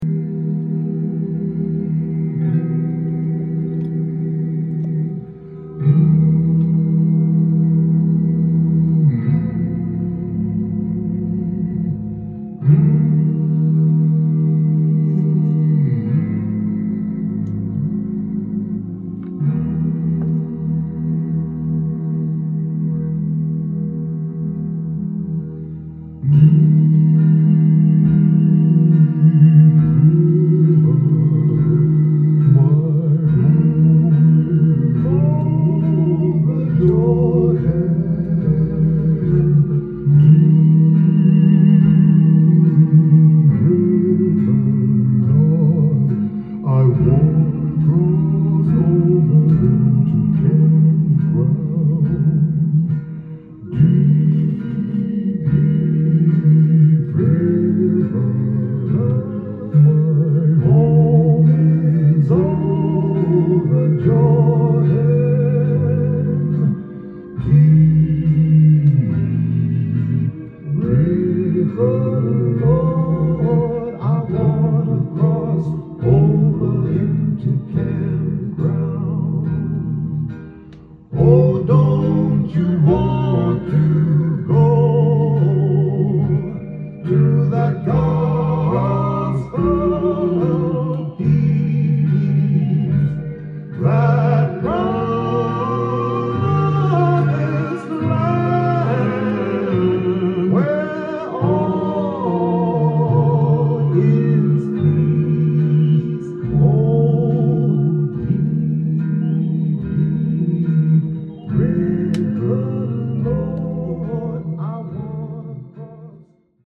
ジャンル：GOSPEL
店頭で録音した音源の為、多少の外部音や音質の悪さはございますが、サンプルとしてご視聴ください。